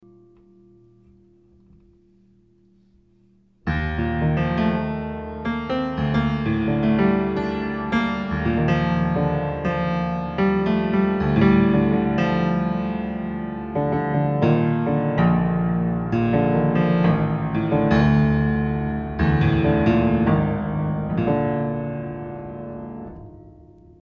These are more piano improvisations on my 120 year old Beckwith piano, late at night, bored with nothing to do.